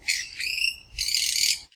sounds_bat_02.ogg